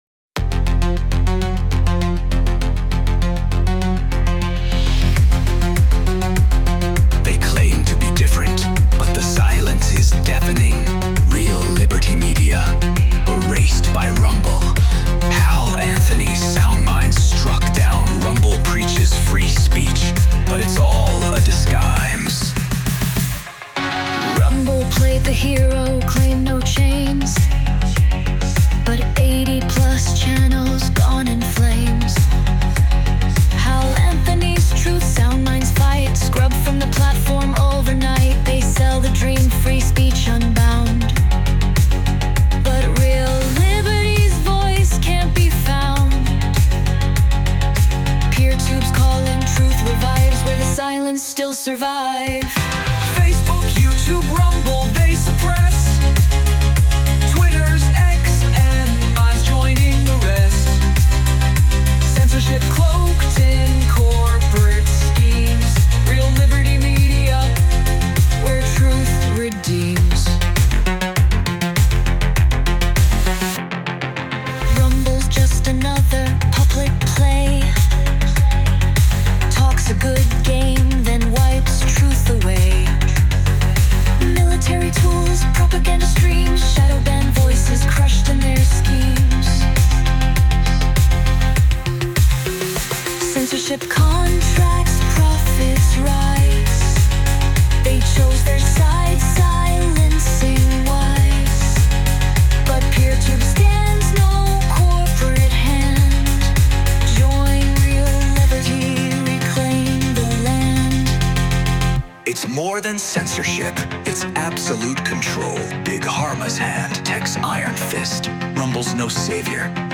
Instrumental - Real Liberty Media Dot XYZ-- 4 mins .mp3